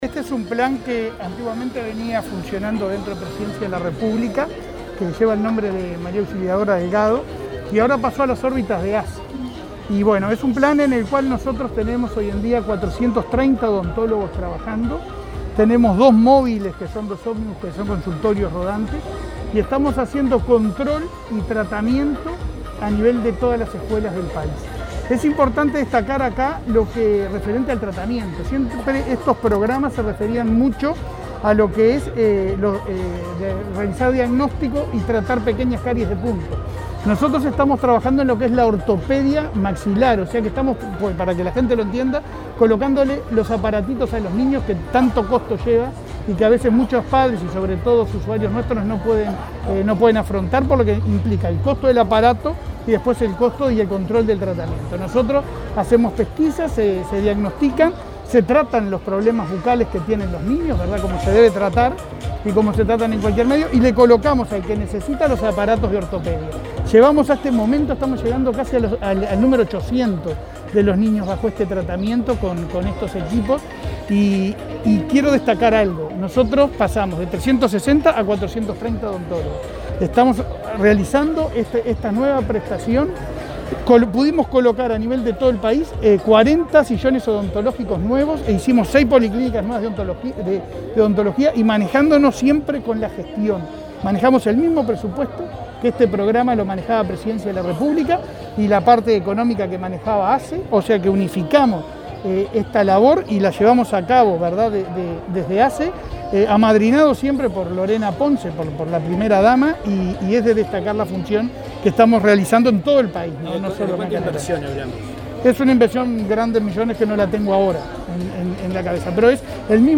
Declaraciones del presidente de ASSE a la prensa
Declaraciones del presidente de ASSE a la prensa 26/10/2021 Compartir Facebook X Copiar enlace WhatsApp LinkedIn El presidente de la Administración de los Servicios de Salud del Estado (ASSE), Leonardo Cipriani, participó de una actividad con motivo de la Semana de la Salud Bucal en Las Piedras y, luego, dialogó con la prensa.